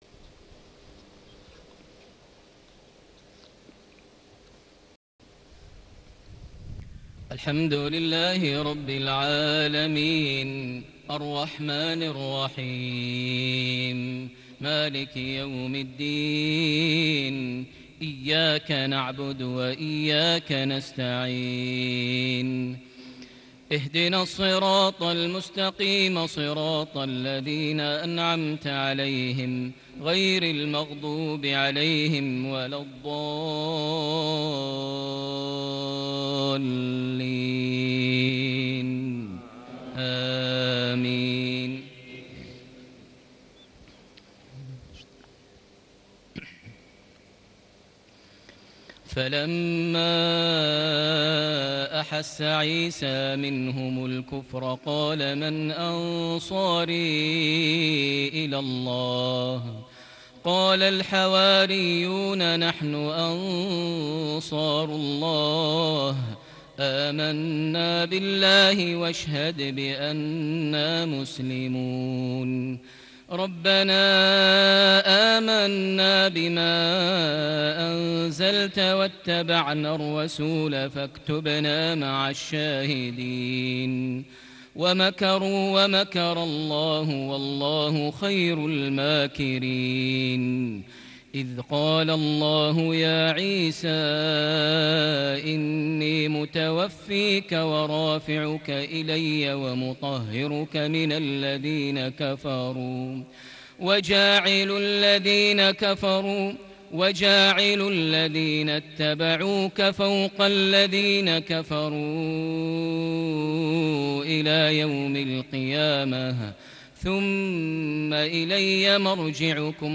صلاة المغرب ٢٧ربيع الأول ١٤٣٨هـ سورة آل عمران ٥٢-٦٠ > 1438 هـ > الفروض - تلاوات ماهر المعيقلي